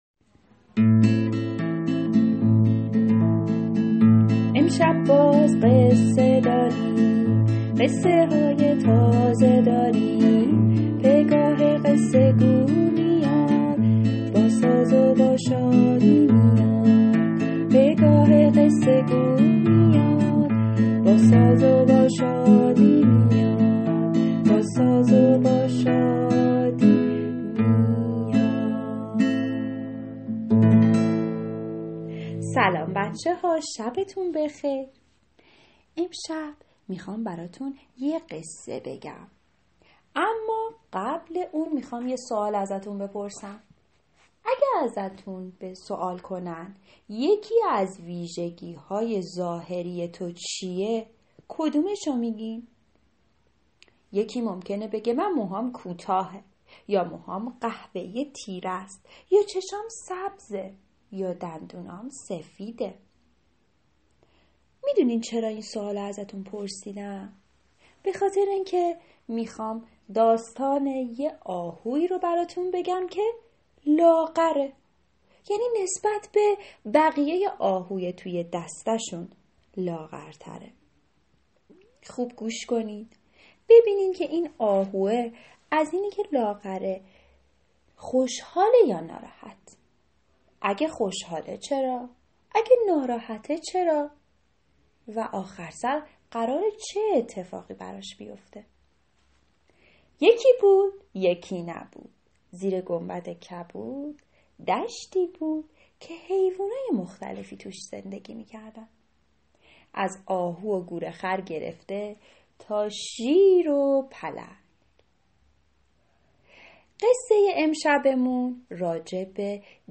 قصه کودکانه صوتی آهوی لاغر